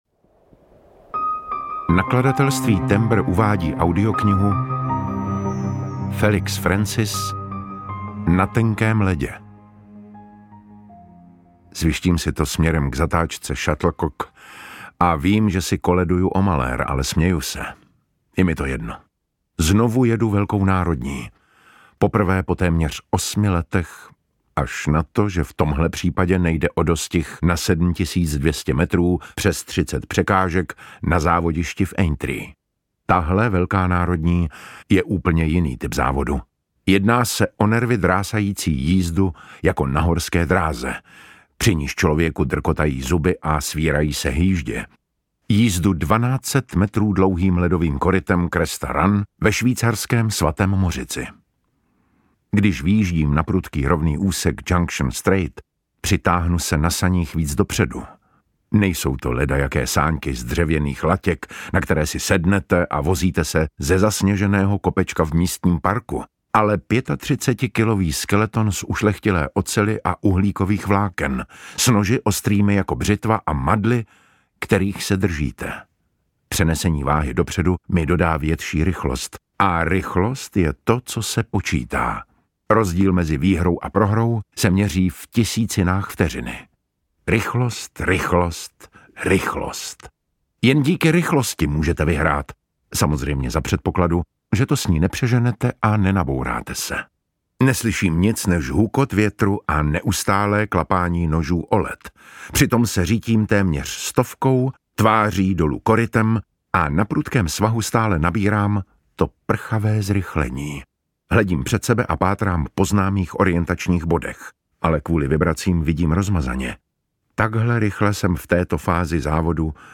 Audiobook
Read: David Matásek